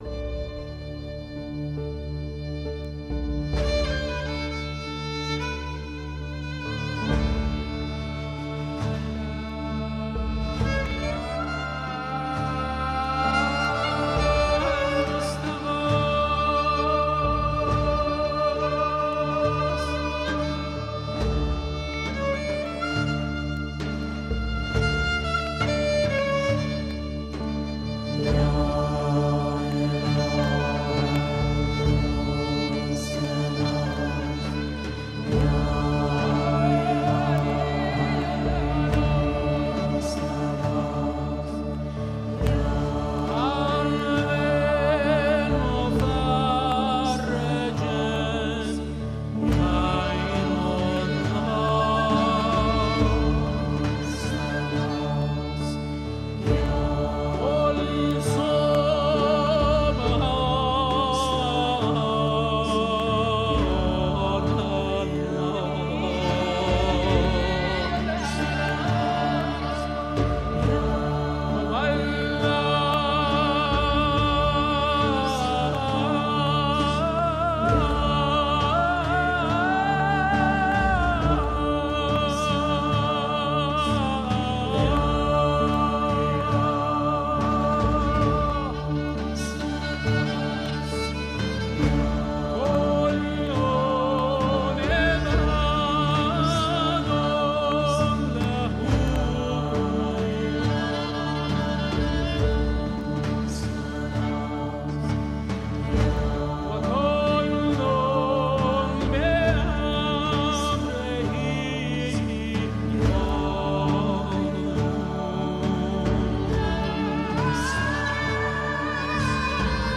Radio Euskadi HAGASELALUZ Presos de conciencia en Irán : La cuestión Bahá'í. Última actualización: 07/06/2015 10:15 (UTC+2) Limpieza cultural en Irán , servicio a la comunidad y solidaridad internacional . Tres pilares que mantenemos en la entrevista